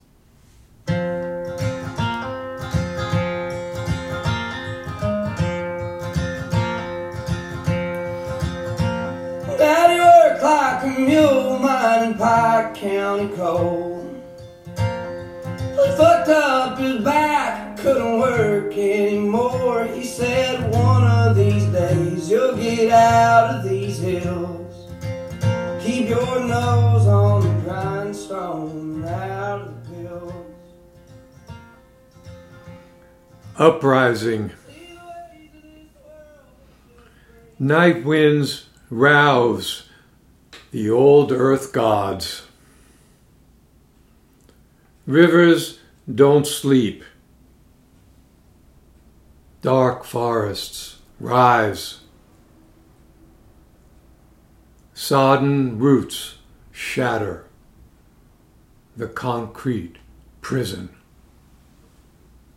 Reading of “Uprising” with music by Tyler Childers